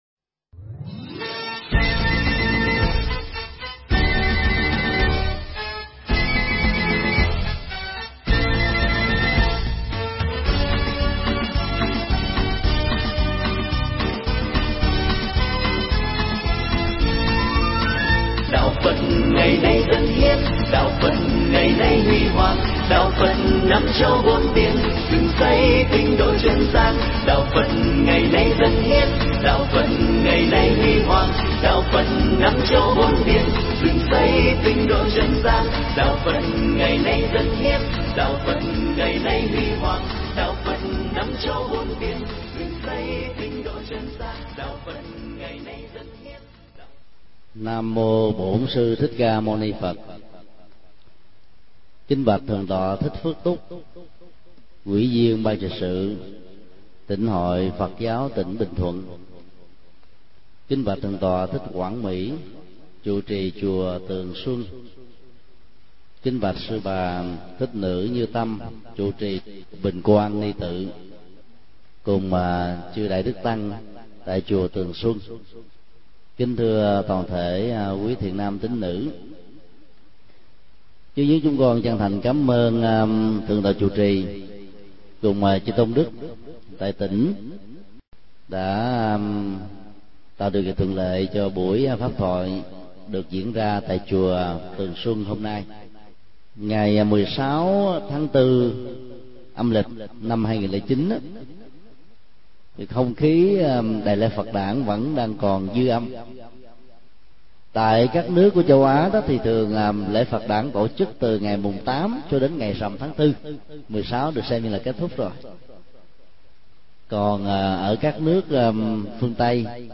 Thuyết pháp
giảng tại Chùa Tường Xuân – Tp Phan Thiết